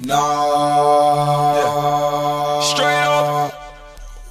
Naaaaaaaaaaaaa (Straight Up)(1).wav